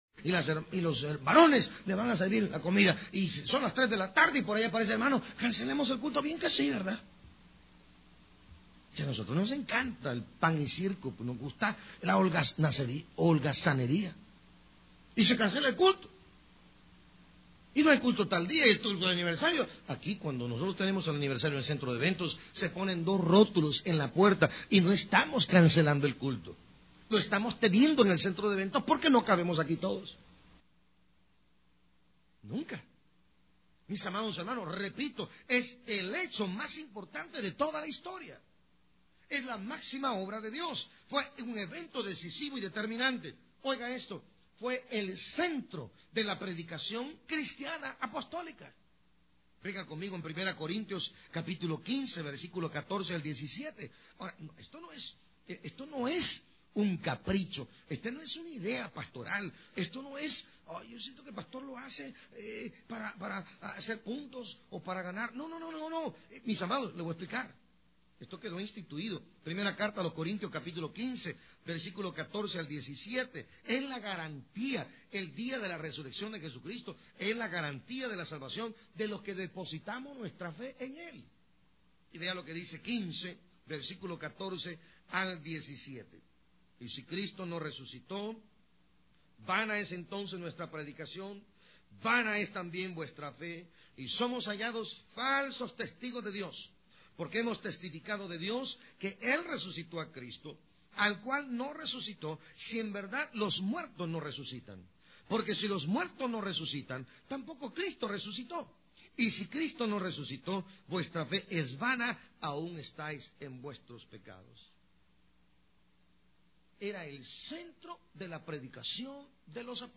Sermones en audio y vídeo, fotografías, eventos y mucho más ¡Queremos servirle!